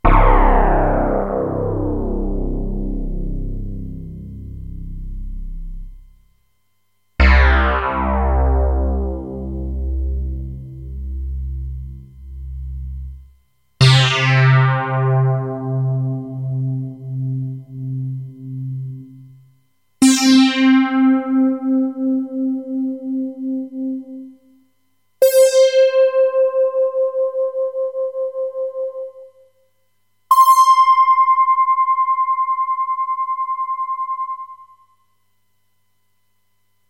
Descarga de Sonidos mp3 Gratis: sintetizador 15.